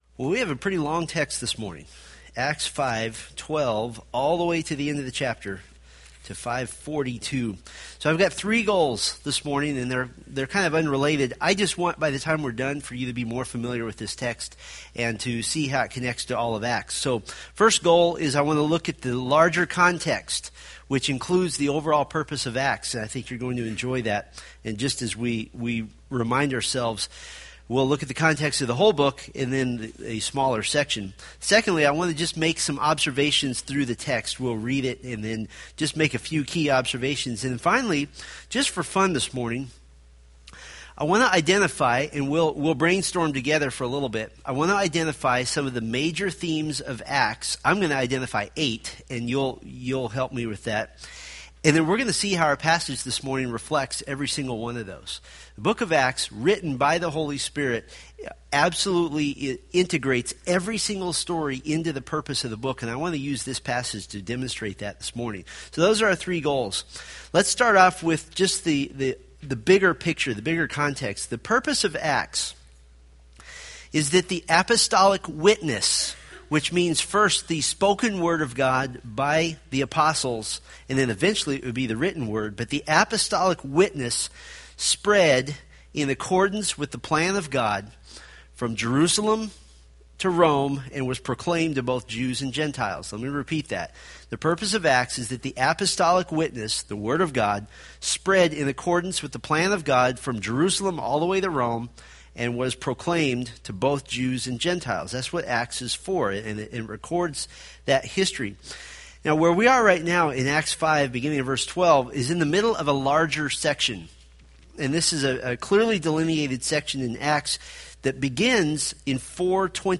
Date: Feb 9, 2014 Series: Acts Grouping: Sunday School (Adult) More: Download MP3